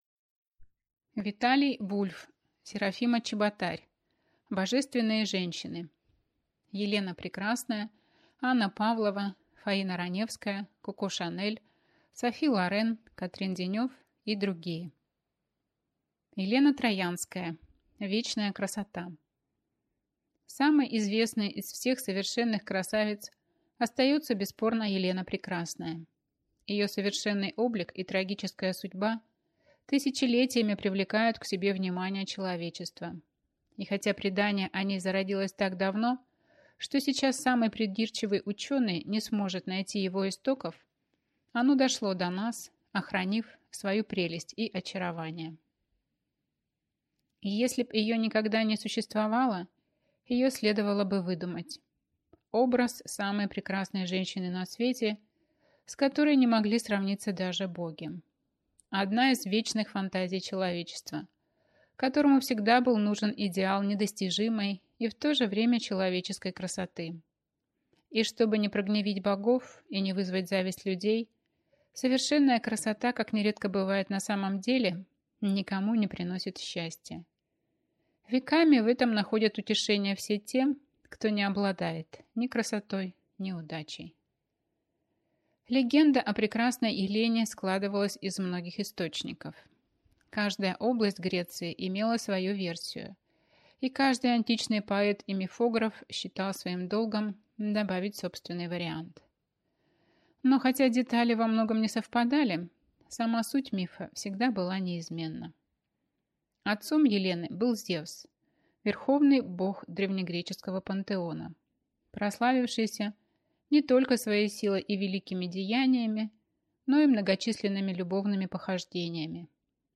Аудиокнига Божественные женщины. Елена Прекрасная, Анна Павлова, Фаина Раневская, Коко Шанель, Софи Лорен, Катрин Денев и другие | Библиотека аудиокниг